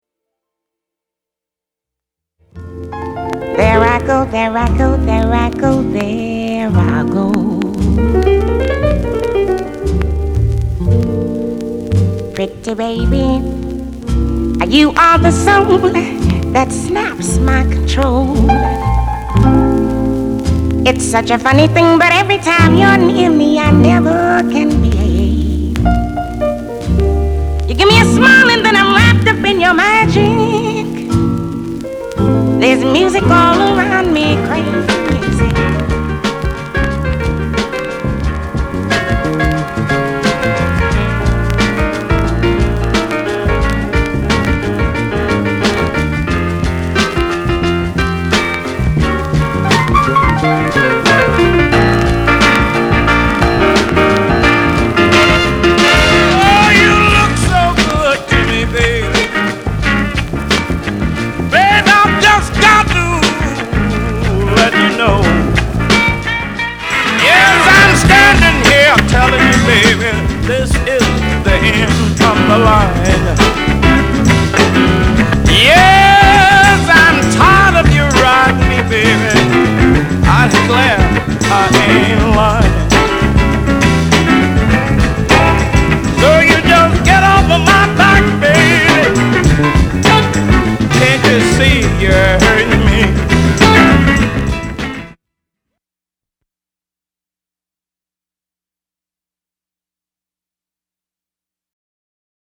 category Vocal
Path Yahoo Bid > Music > Vinyl Records > Jazz > Vocal